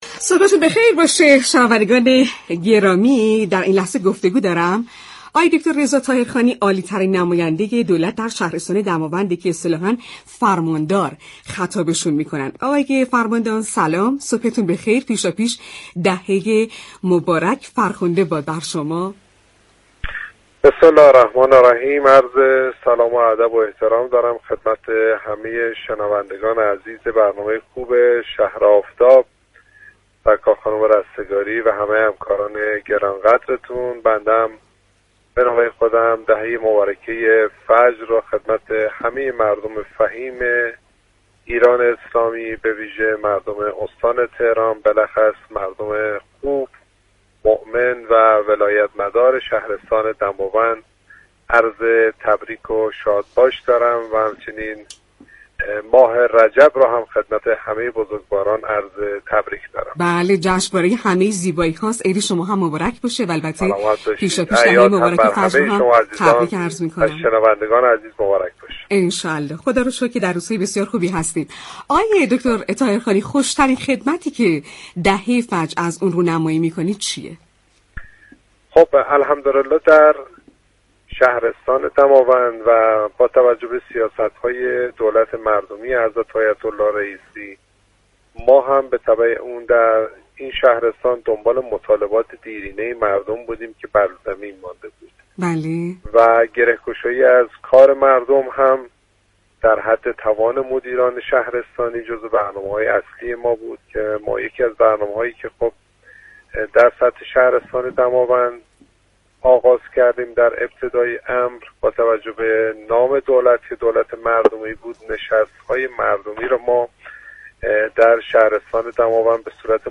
به گزارش پایگاه اطلاع رسانی رادیو تهران، رضا طاهرخانی فرماندار شهرستان دماوند در گفت و گو با «شهر آفتاب» رادیو تهران اظهار داشت: با تبعیت از سیاست‌های دولت مردمی آیت الله رئیسی به دنبال مطالبات دیرینه مردم در این شهرستان هستیم.